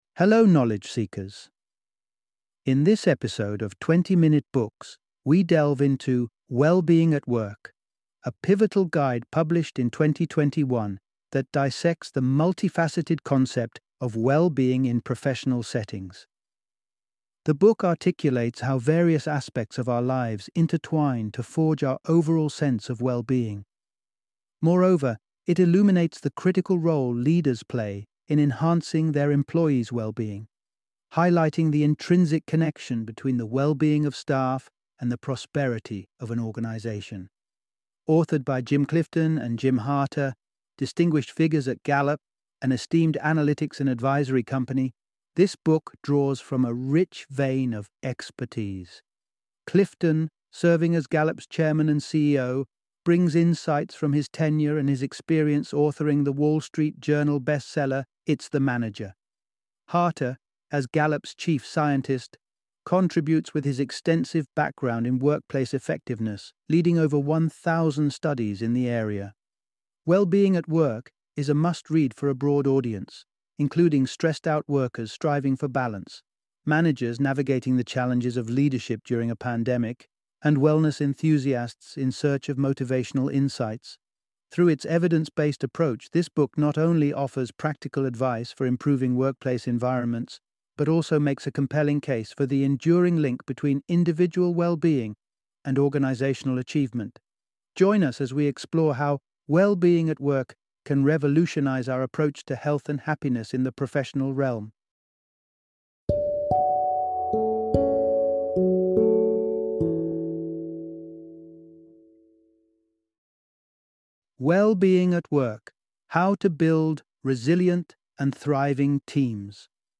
Wellbeing at Work - Audiobook Summary